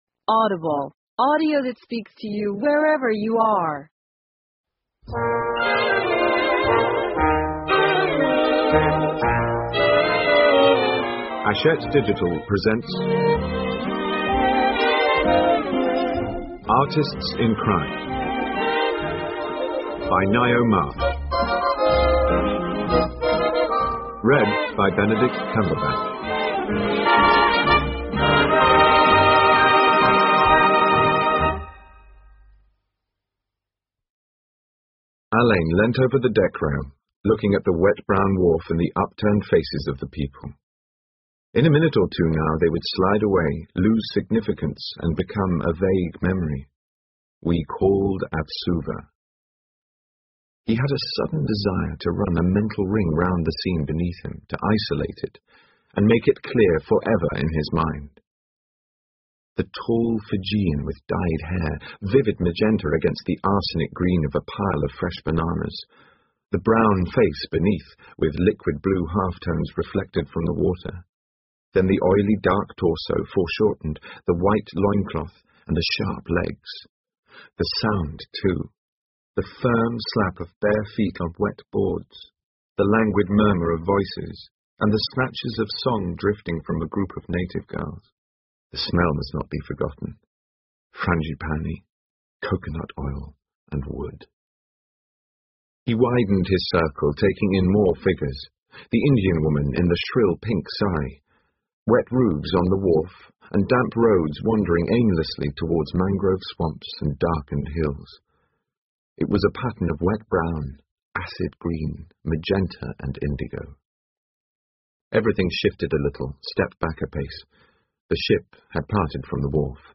英文广播剧在线听 Artists in Crime 01 听力文件下载—在线英语听力室